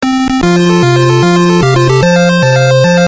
このBGM・SEが使用されているタイトルをお答えください。